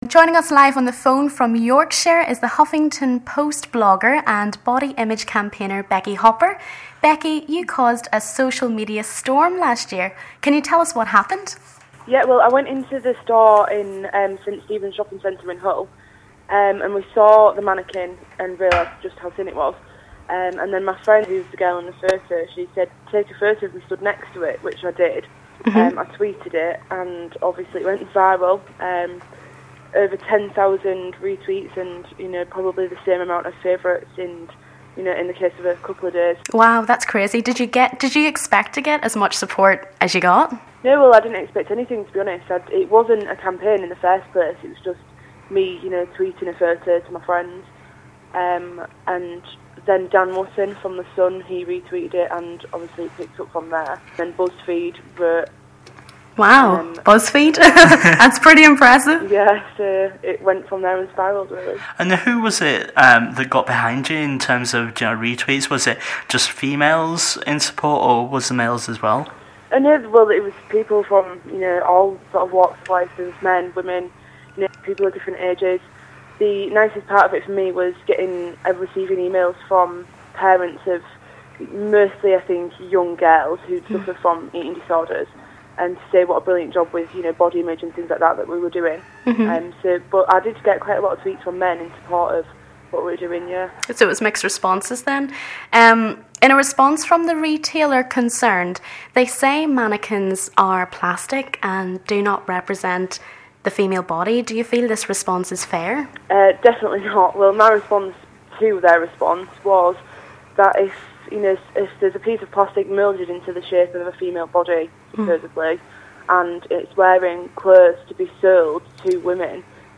Body Image: How thin is too thin? Interview